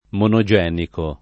[ mono J$ niko ]